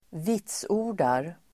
Ladda ner uttalet
vitsorda verb, testify Grammatikkommentar: A & x/att + S Uttal: [²v'it:so:r_dar] Böjningar: vitsordade, vitsordat, vitsorda, vitsordar Definition: ge vitsord om, bekräfta, intyga (vouch for, certify)